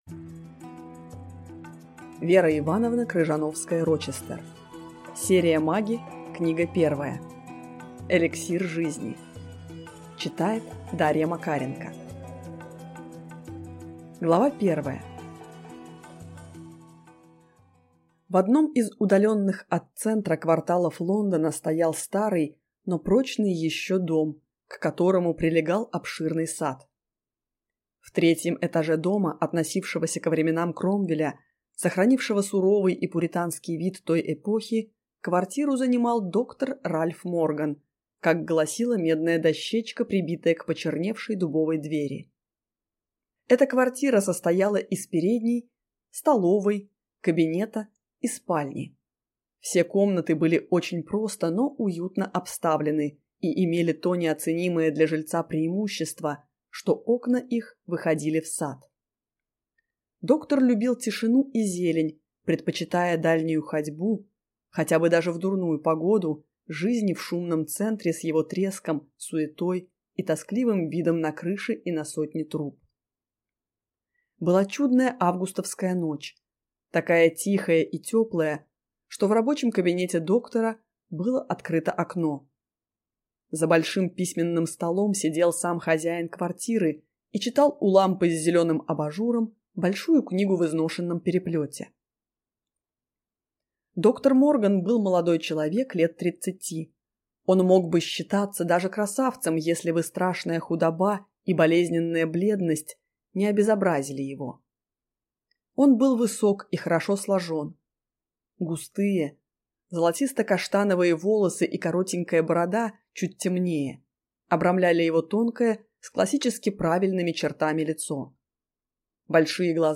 Аудиокнига Эликсир жизни | Библиотека аудиокниг